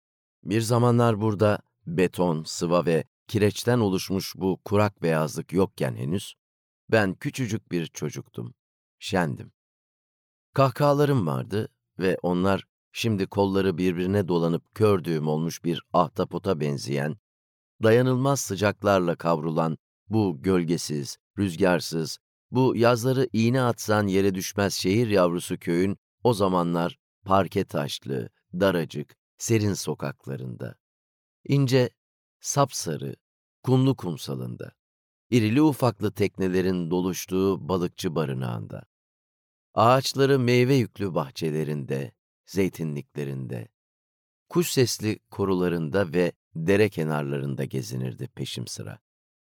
Sesli Kitap
Deneyimli seslendirme sanatçılarının okuduğu, editörlüğümüz tarafından özenle denetlenen sesli kitap koleksiyonumuzun ilk örneklerini paylaşmaktan sevinç duyuyoruz.